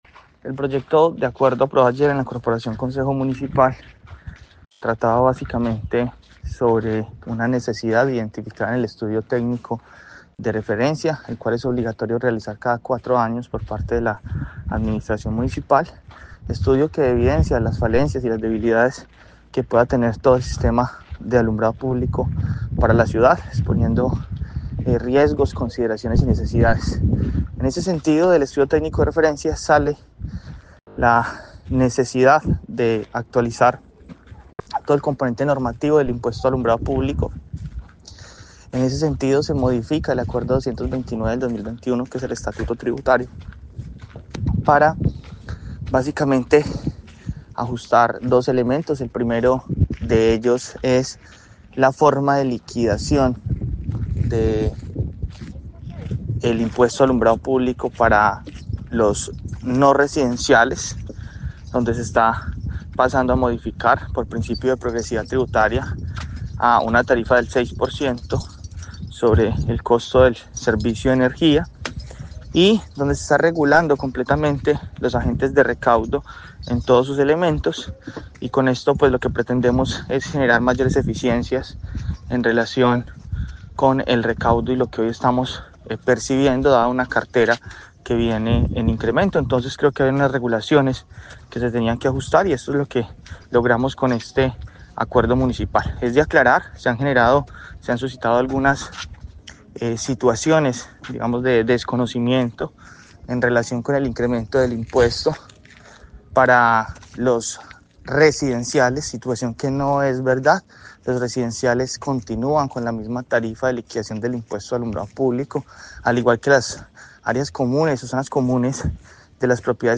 Yeison Pérez, secretario de hacienda de Armenia
En Caracol Radio Armenia hablamos con el secretario de hacienda de la alcaldía Yeisón Andrés Pérez que explicó en que consiste el proyecto